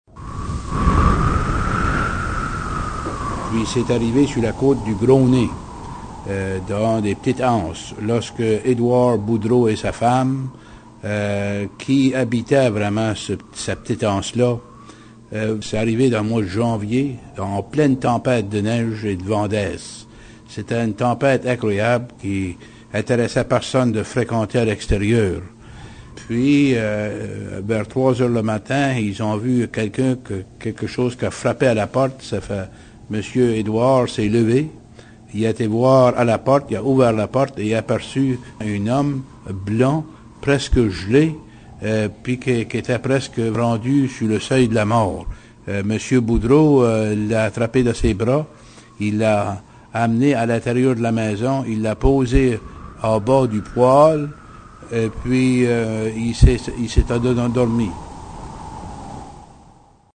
au coin du feu et nous raconte :